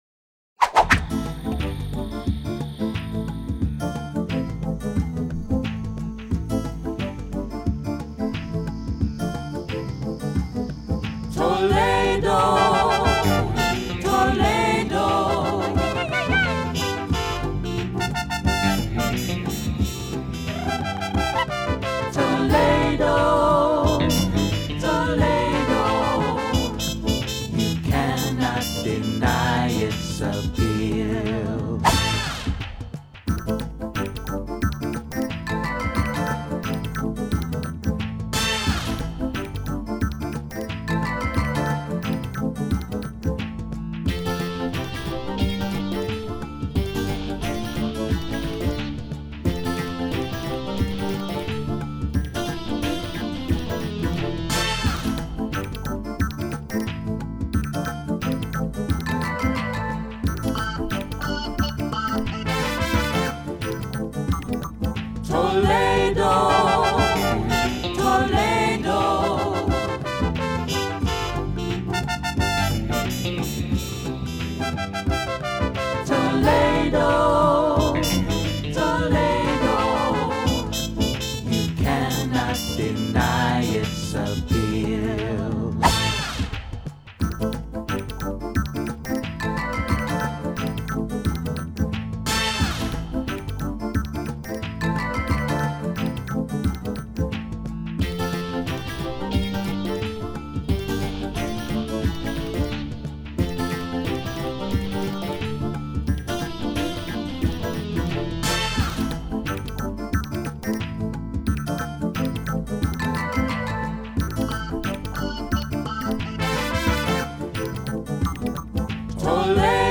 backing track